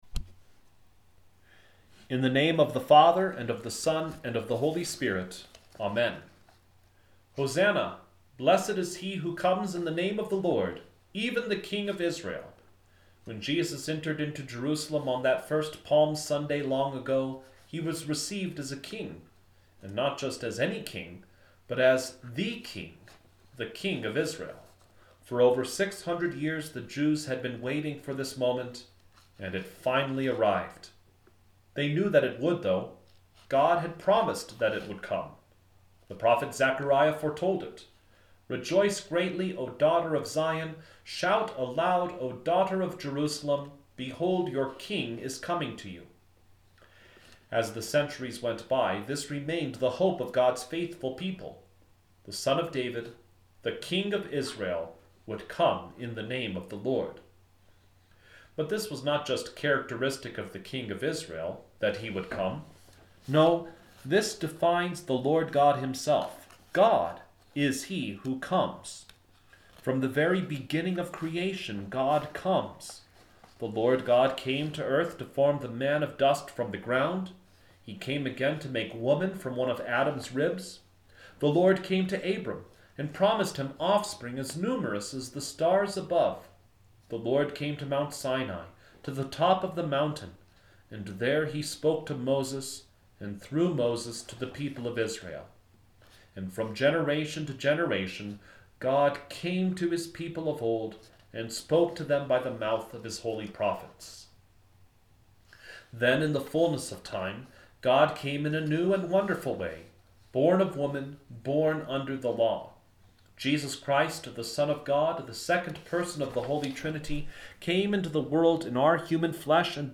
Palm Sunday